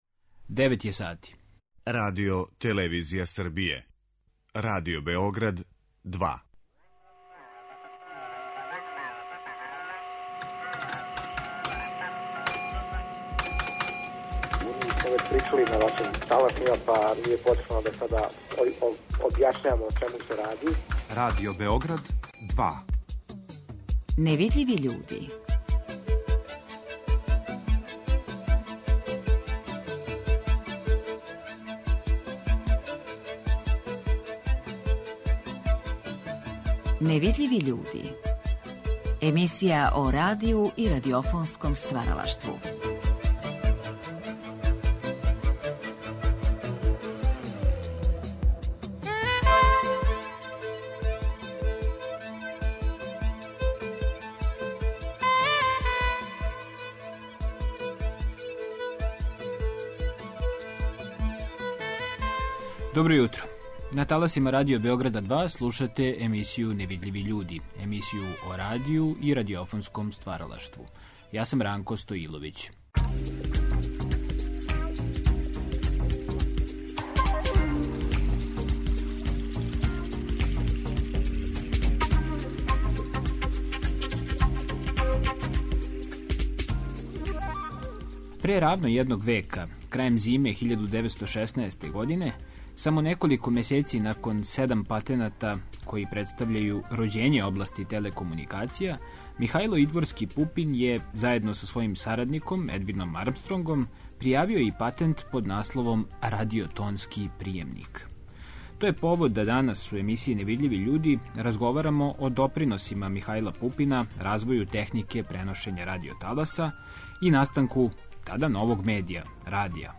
Ови разговори емитовани су у оквиру циклуса "Гост Другог програма" почетком осамдесетих година прошлог века.